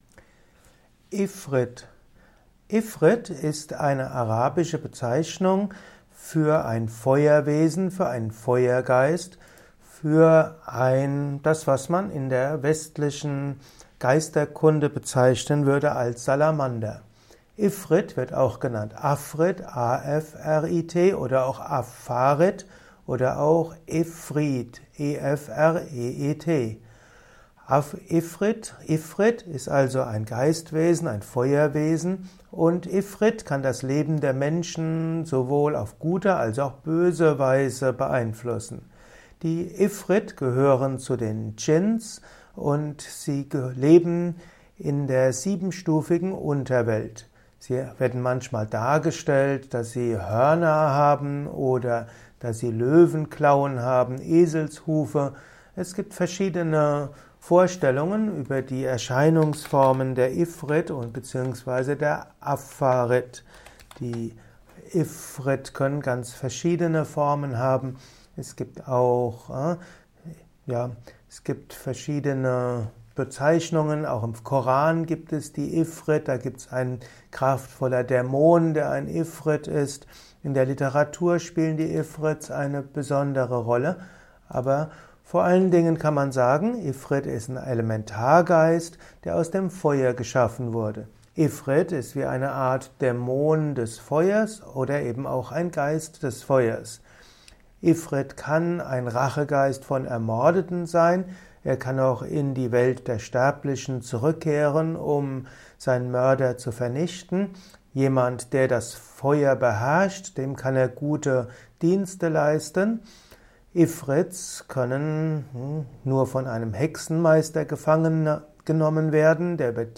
eines Videos aus dem Youtube Esoterik-Kanal.